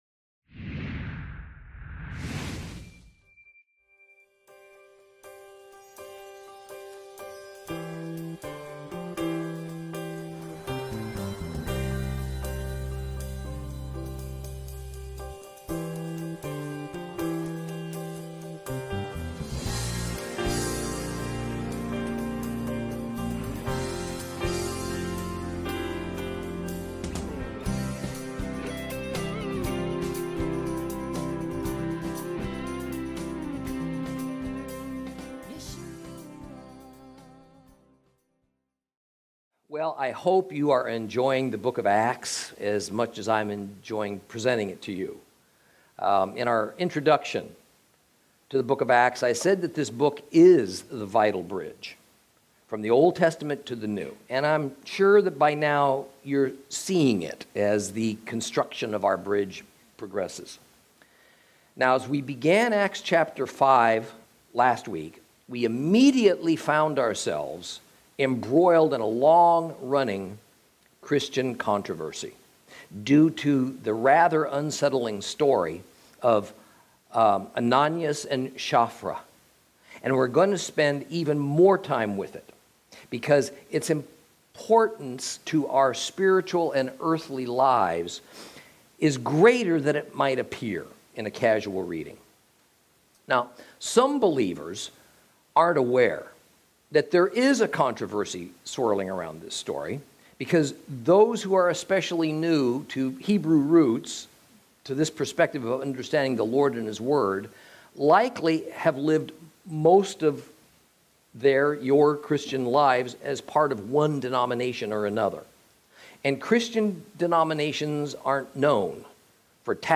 Lesson 13 Ch5 - Torah Class